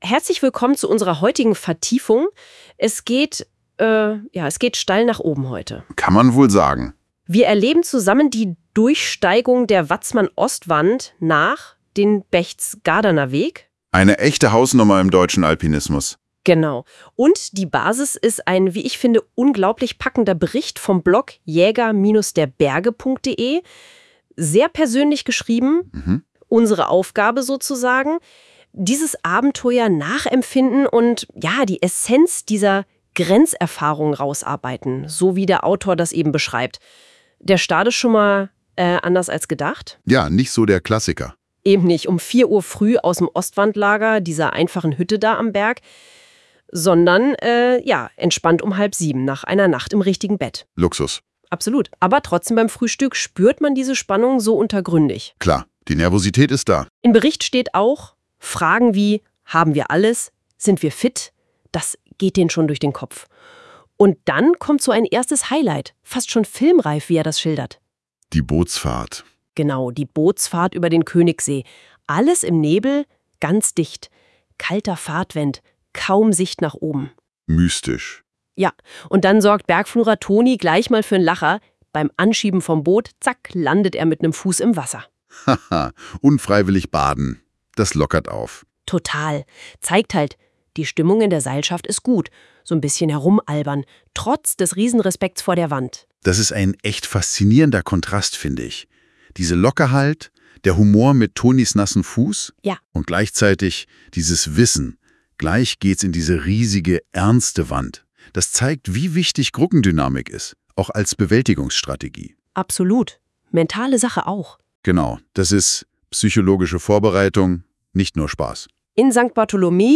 *AI Zusammenfassung